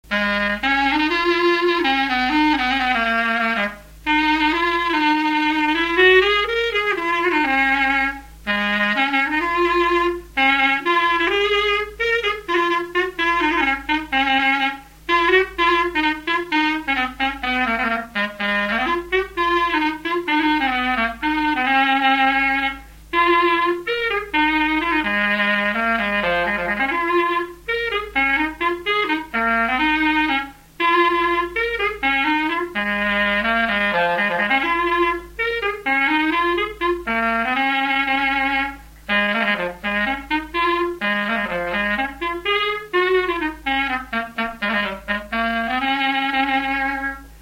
Mémoires et Patrimoines vivants - RaddO est une base de données d'archives iconographiques et sonores.
Résumé instrumental
circonstance : fiançaille, noce
Pièce musicale inédite